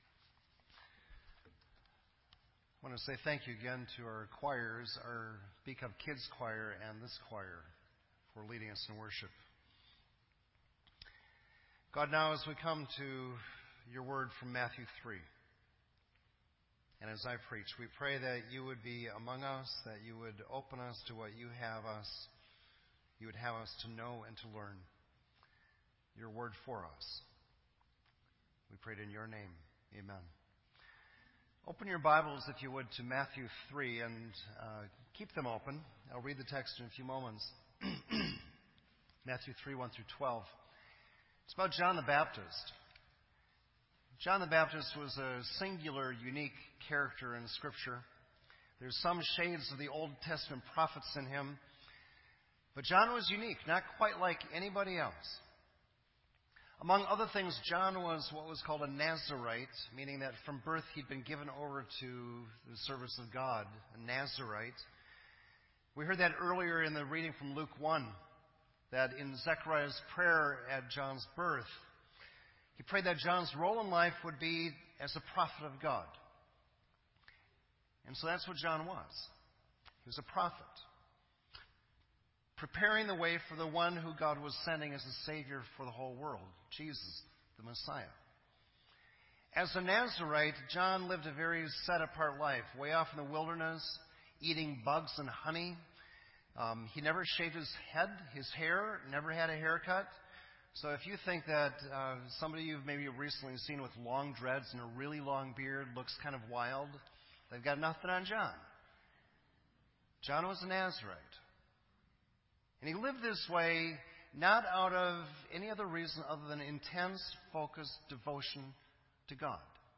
sermon
This entry was posted in Sermon Audio on December 5